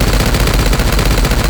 AssaultCanon_1p.wav